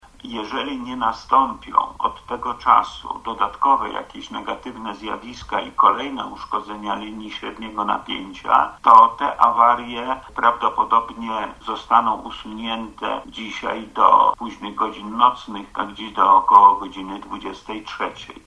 Na godzinę 12 mieliśmy około 40 tysięcy odbiorców bez prądu – mówi wojewoda lubelski Lech Sprawka: